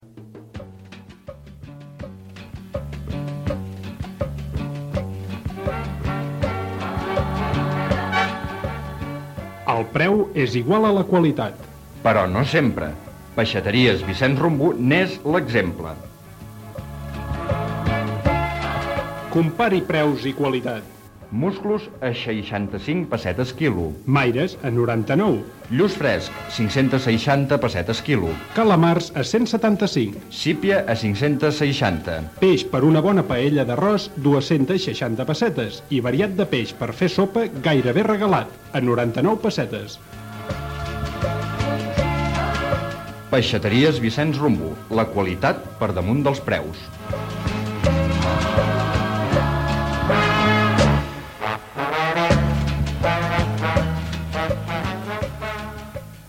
FM